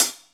paiste hi hat9 close.wav